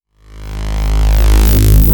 VEC3 Reverse FX
VEC3 FX Reverse 12.wav